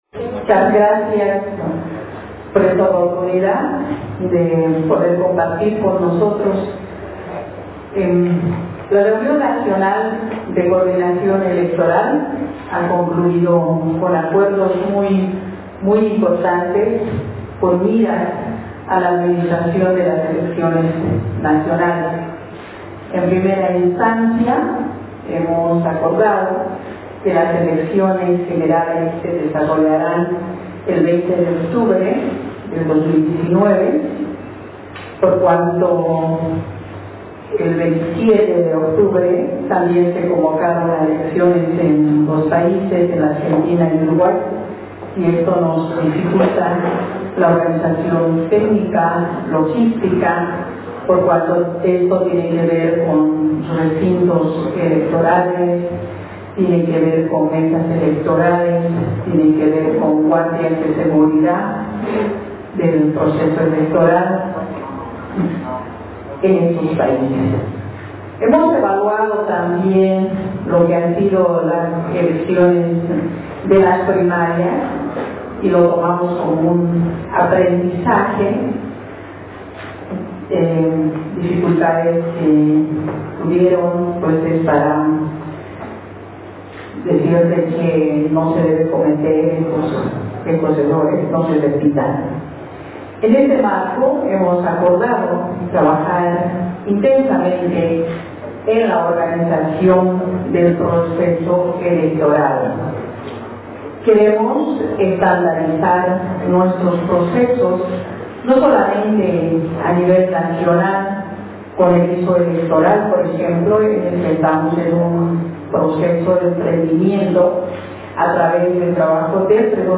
* Escuche el audio de la Conferencia de prensa donde anuncia la fecha de las Elecciones Generales 2019